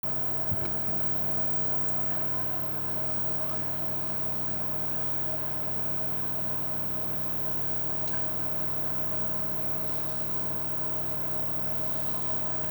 De Multiplus-II produceert best wel wat geluid wanneer deze lekker inbedrijf is.
Geluid MultiuPlus-II 5000.m4a